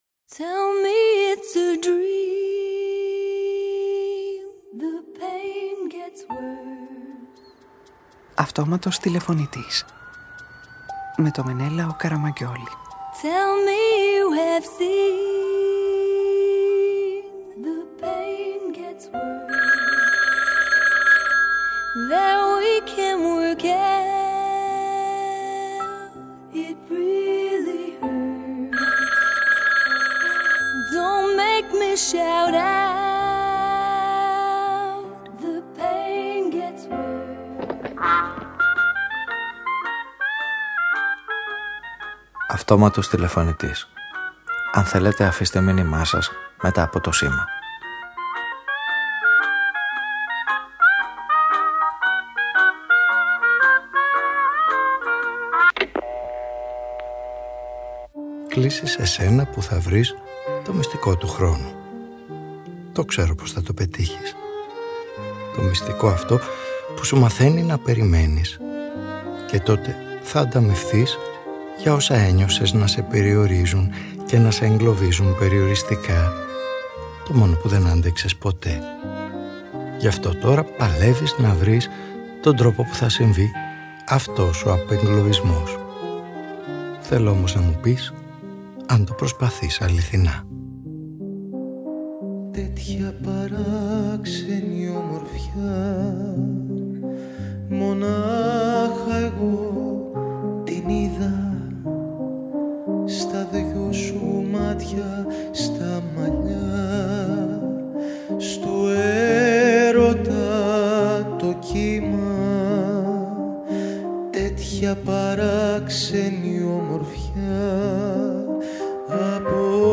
Μέσα από ένα μήνυμα που είναι γεμάτο προτροπές, νέα τραγούδια και παλιά, ερωτήσεις και μηνύματα κάθε είδους που προοιωνίζουν όσα διεκδικούν όσοι πολεμάνε με το χρόνο καθημερινά.